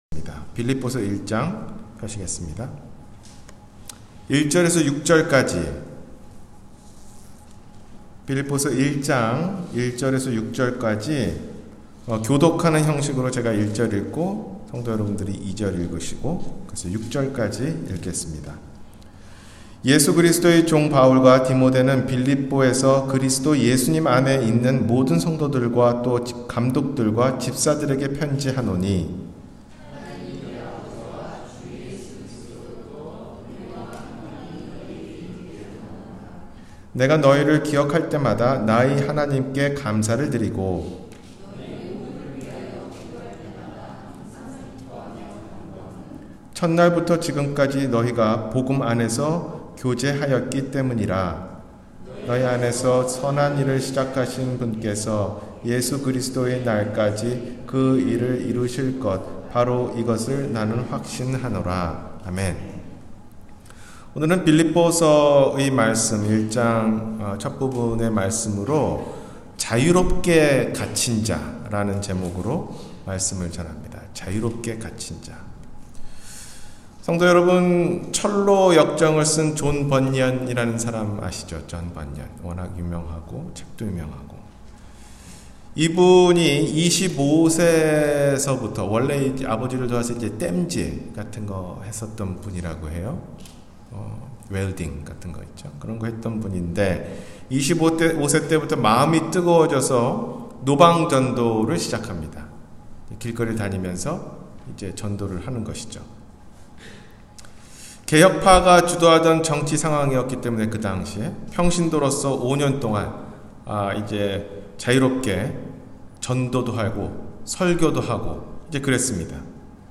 자유롭게 갇힌 자 – 주일설교